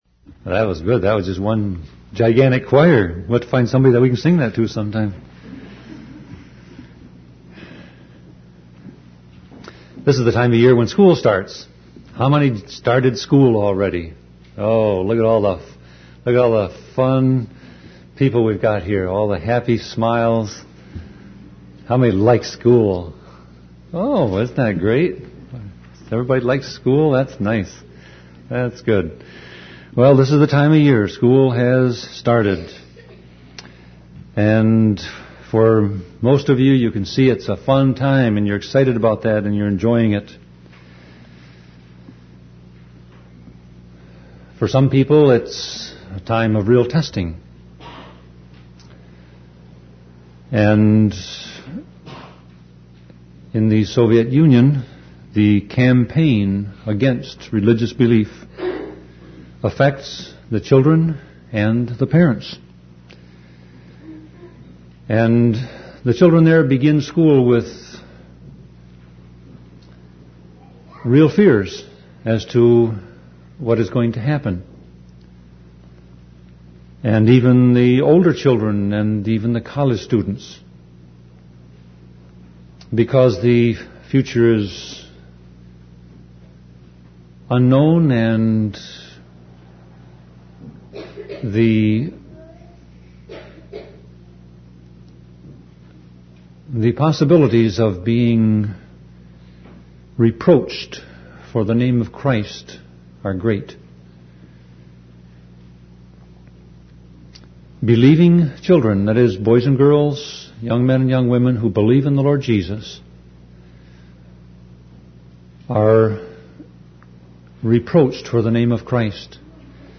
Sermon Audio Passage: 1 Peter 4:14-19 Service Type